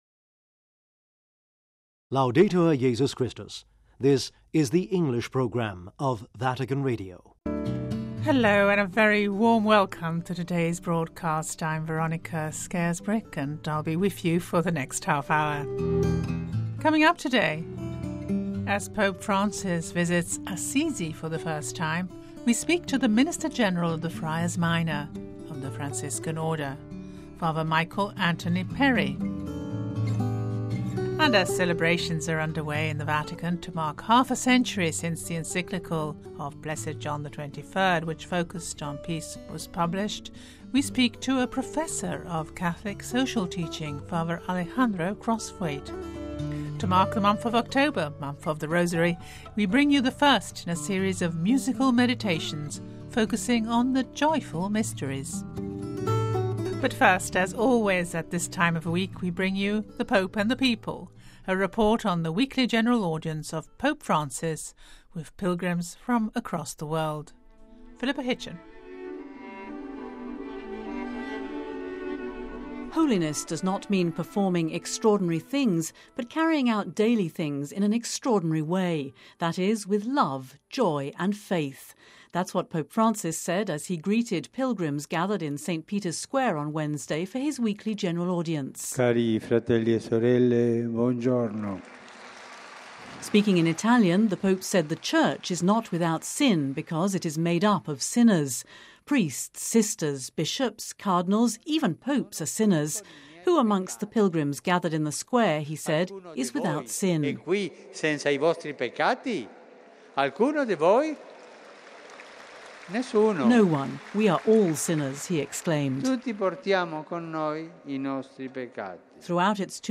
Report from Assisi
Joyful Mystery: a musical meditation - To mark October, month of the Rosary, we bring you the first in a series of musical meditations focusing on the Joyful Mysteries.